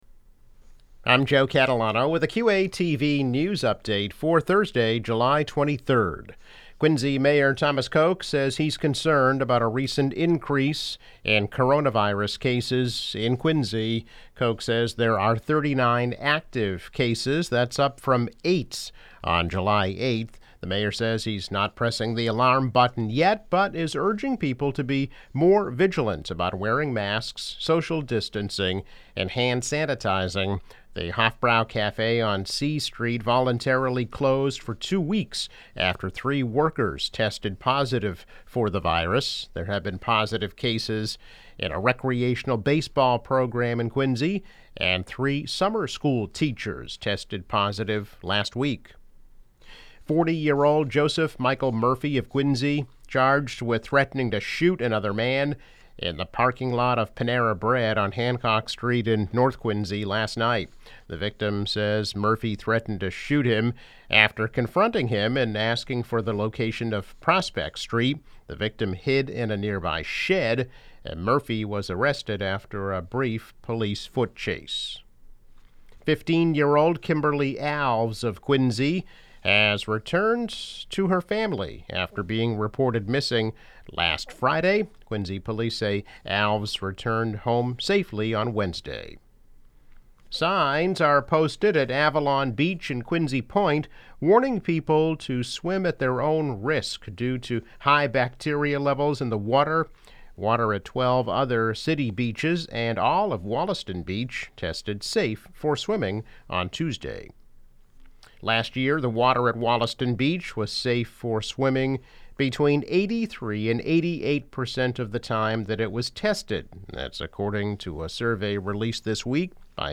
News Update - July 23, 2020